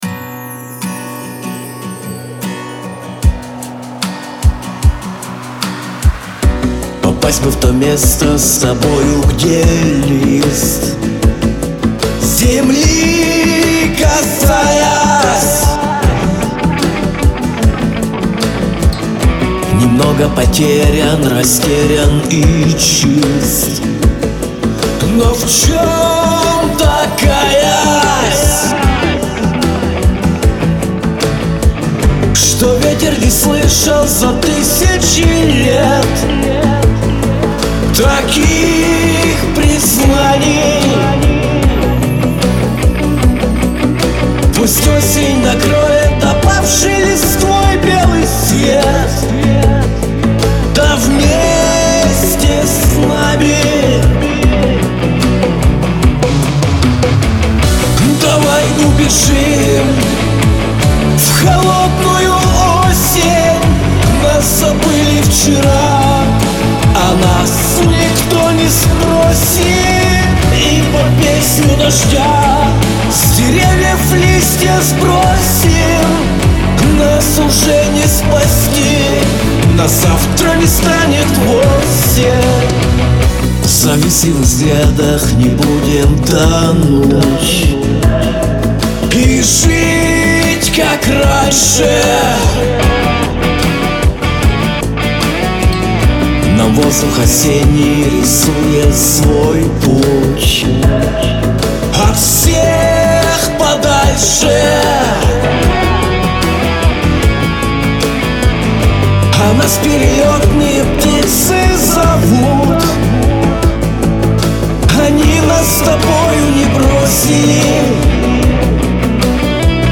Трек размещён в разделе Русские песни / Рок.